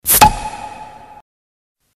Рингтоны без слов , Короткие рингтоны